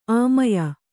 ♪ āmaya